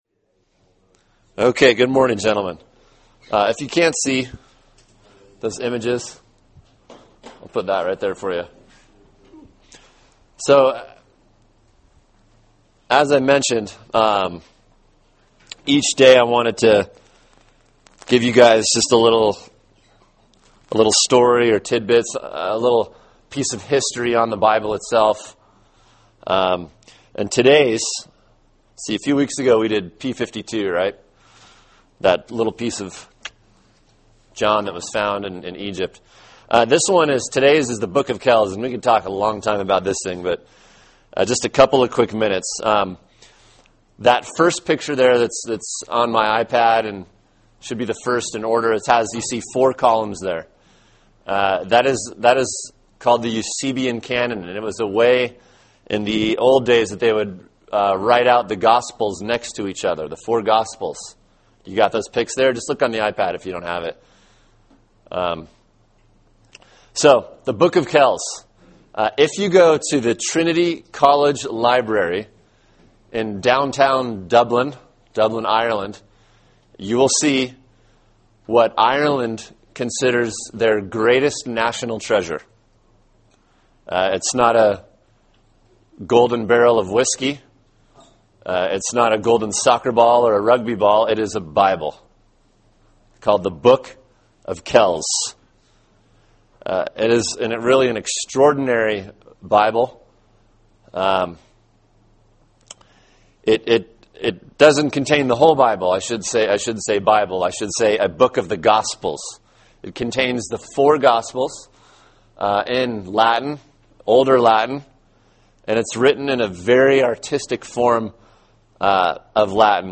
Words of Life: Bibliology Lesson 3: The Necessity and the Inerrancy of Scripture